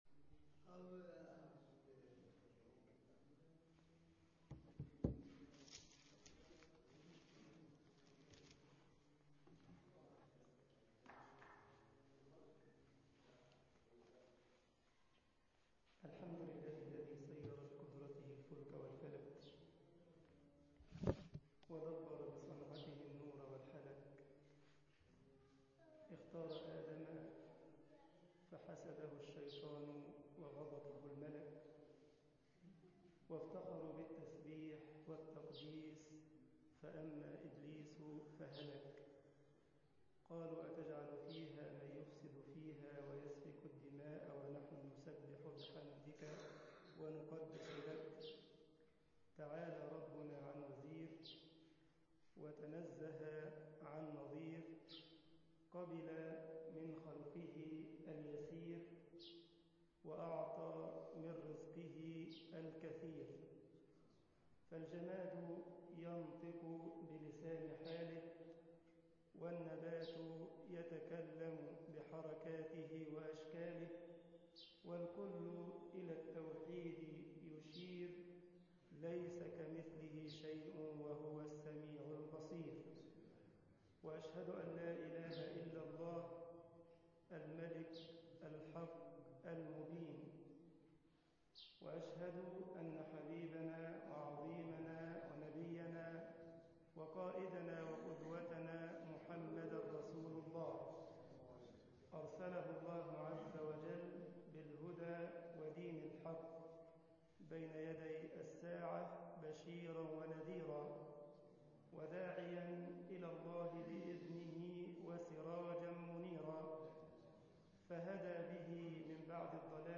مسجد الكبير ـ الباجور المنوفية